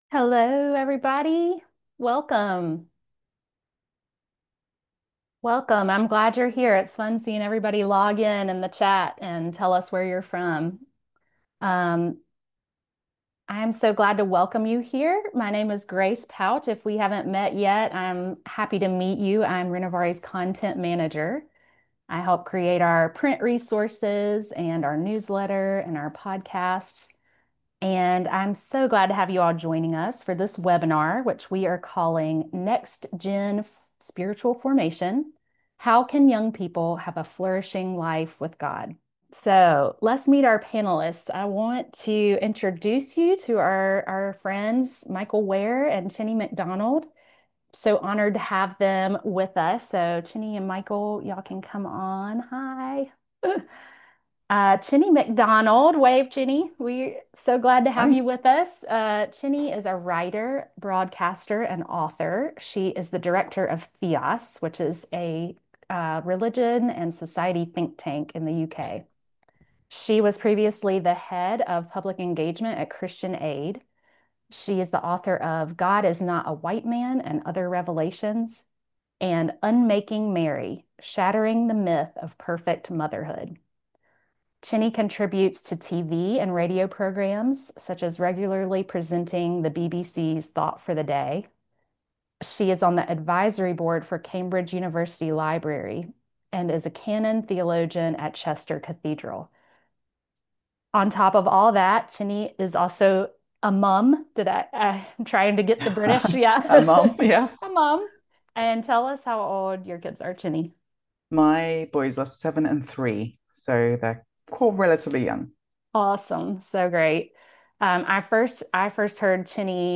Webinar-Next-Gen-Spiritual-Formation.mp3